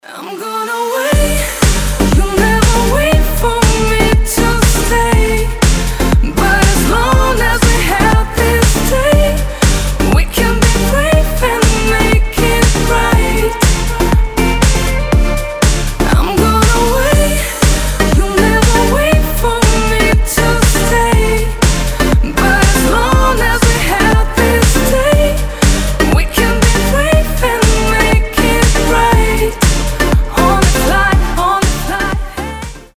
Танцевальные рингтоны
House , Synth pop , Красивый женский голос
Поп